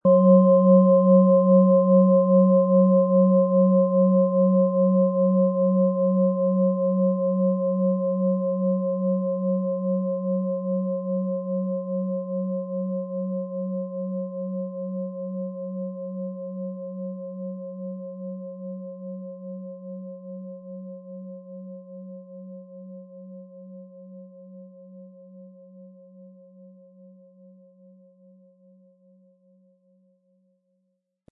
Von Hand getriebene Schale mit dem Planetenton OM-Ton.
• Tiefster Ton: Biorhythmus Geist
Im Sound-Player - Jetzt reinhören können Sie den Original-Ton genau dieser Schale anhören.
PlanetentöneOM Ton & Biorhythmus Geist
MaterialBronze